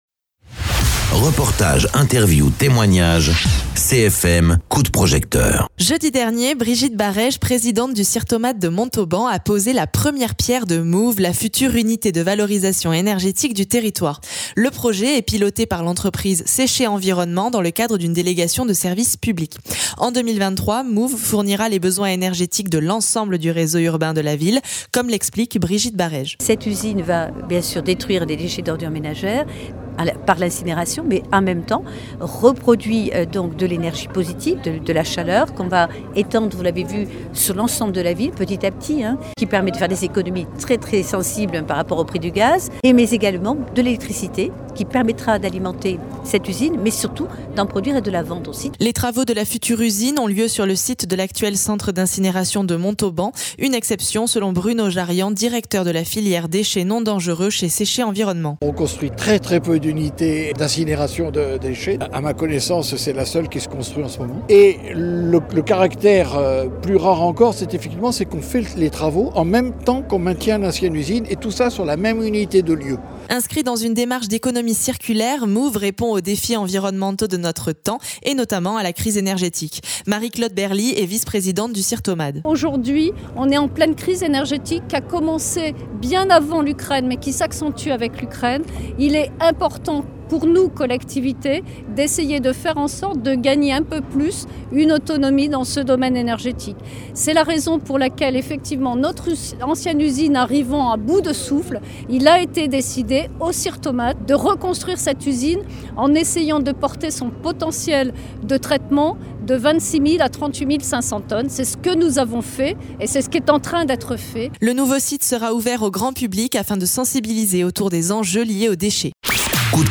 Interviews
Invité(s) : Brigitte Barèges, présidente de SIRTOMAD et maire de Montauban
Marie-Claude Berly, vice-présidente du SIRTOMAD et conseillère communautaire